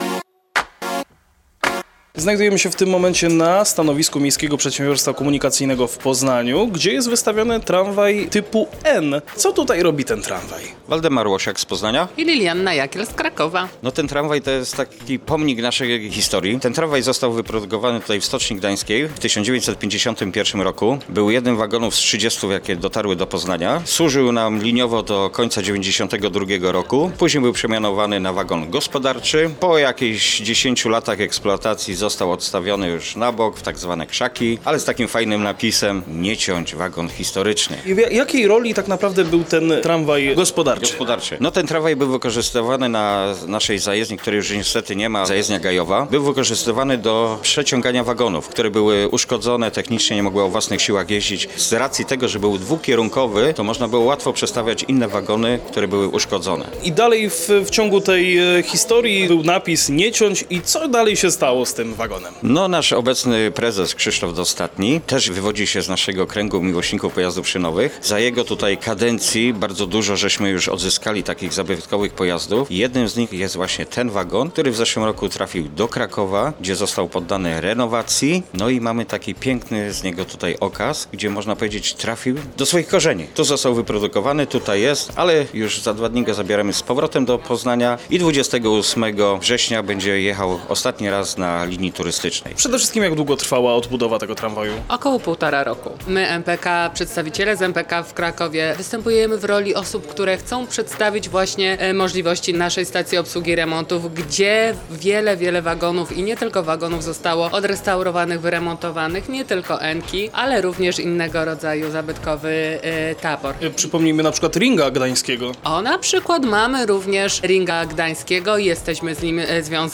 Zapraszamy Was na kolejny wywiad z serii materiałów nagranych podczas 16. Międzynarodowych Targów Kolejowych TRAKO 2025!